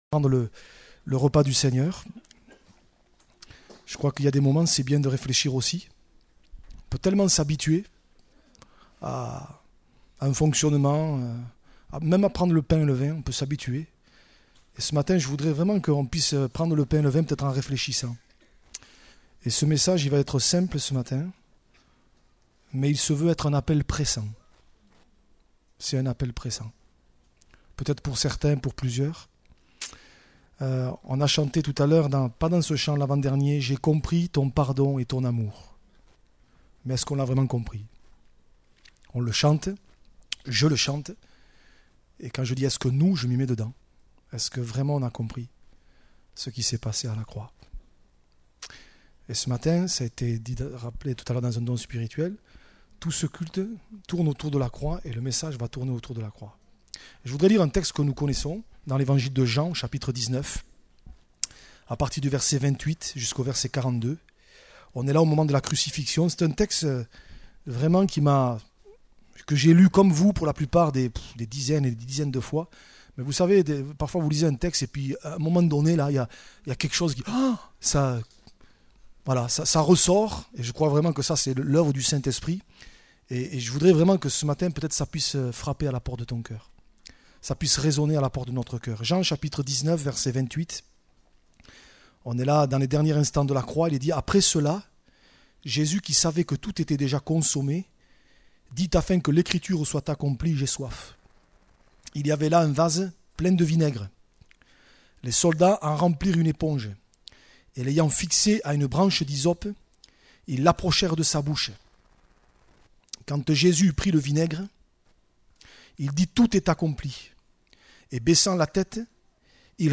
Type De Service: Evangélisation